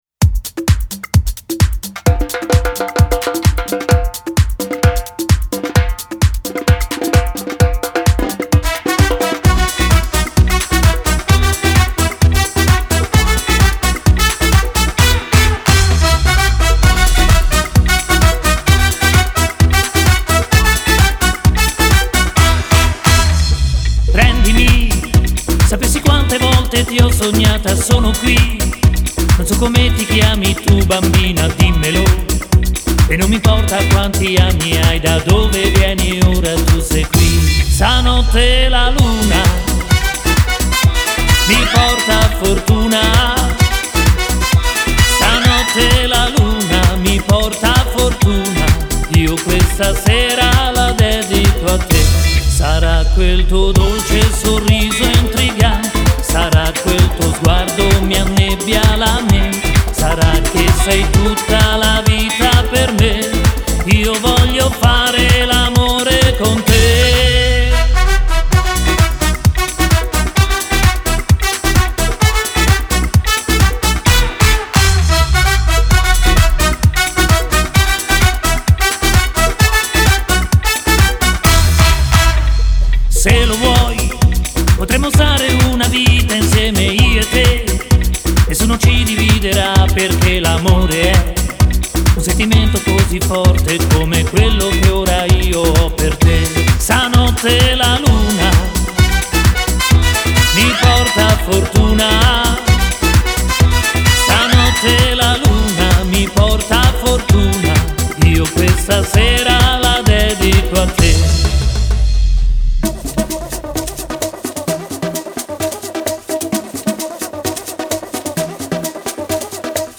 (Samba)